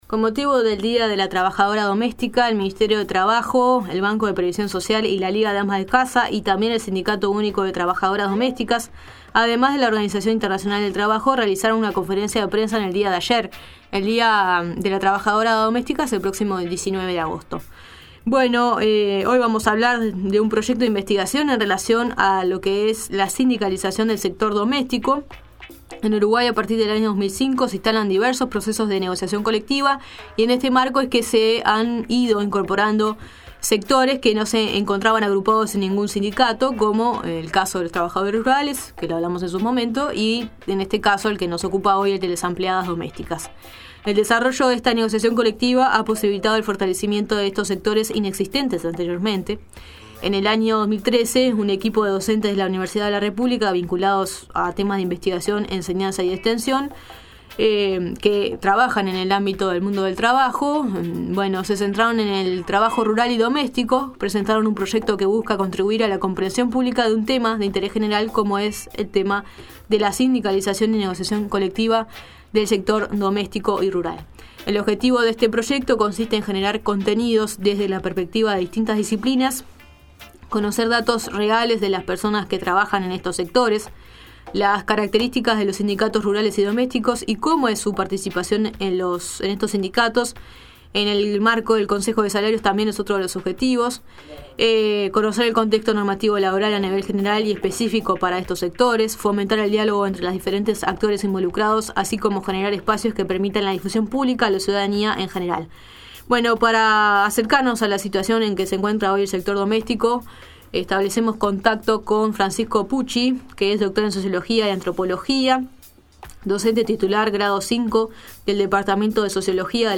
En La Nueva Mañana, hablamos con